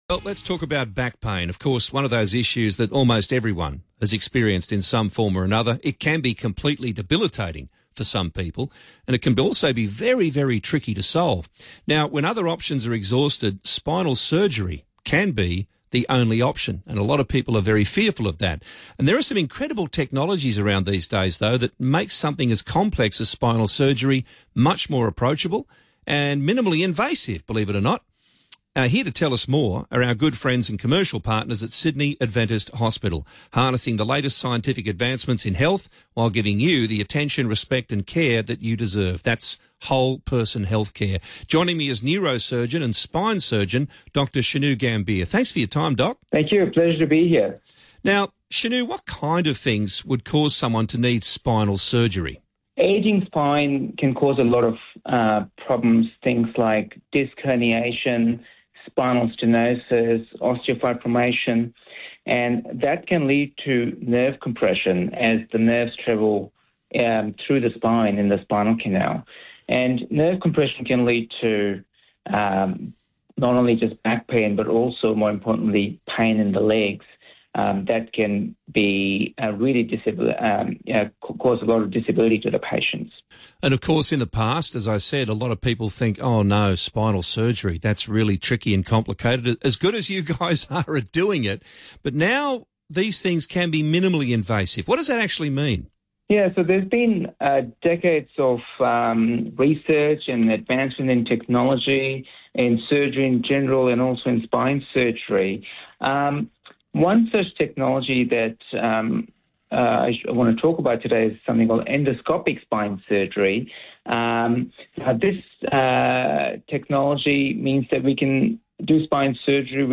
Radio interviews & Mentors in Medicine Podcast
Neurosurgeon & Spine Surgeon